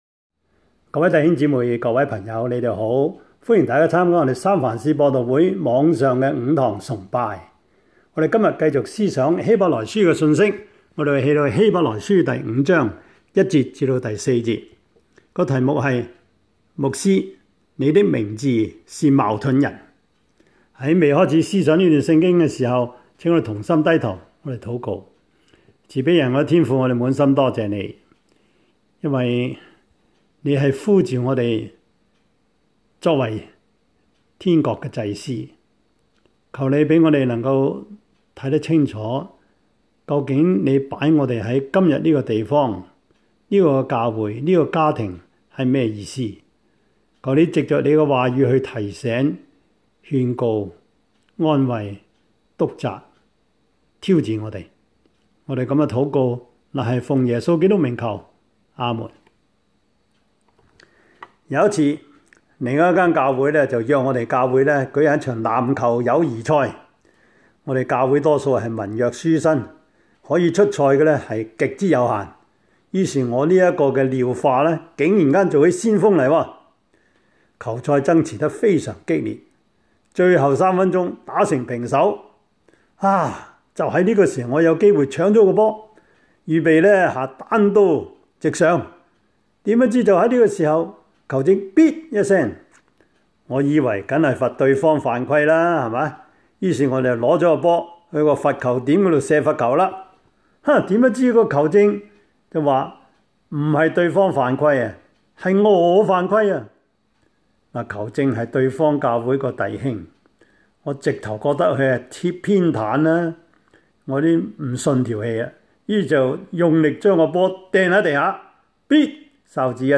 Service Type: 主日崇拜
Topics: 主日證道 « 用愛心彼此問安 夫妻相處之道 – 第十六課 »